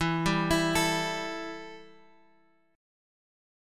Embb5 chord {x 7 5 x 5 5} chord